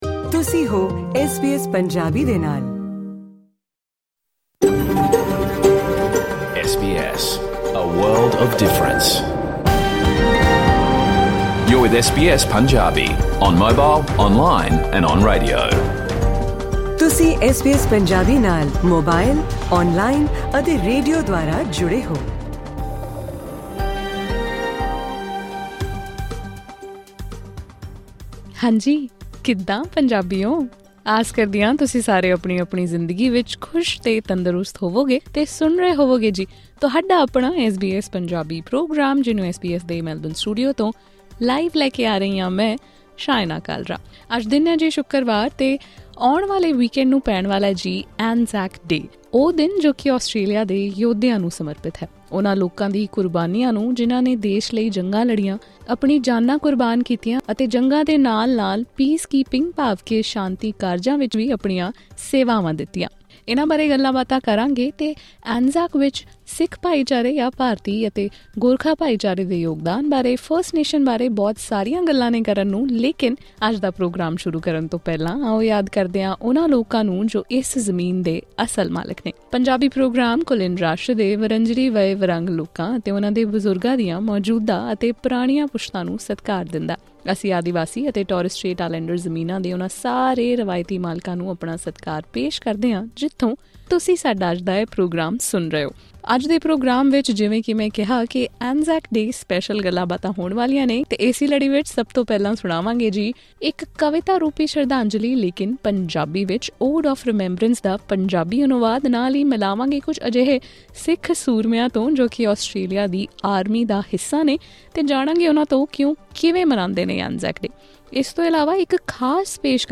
Listen to the SBS Punjabi full radio program: ANZAC Day special
In this ANZAC Day special program, we have dedicated a show to those who have served and sacrificed their lives in the Australian Defence Force, wars, and peacekeeping efforts. From a tribute through reciting the Ode to Remembrance poem in Punjabi to speaking with Australian-Sikh soldiers currently in service and knowing from them how they are celebrating the day, this program has many interesting presentations. Do not miss the special report which reveals how 'pugggaree' worn by every Australian soldier is a tribute to the brotherhood of Indian and Australian soldiers during World War I. All this and more can be enjoyed via this podcast.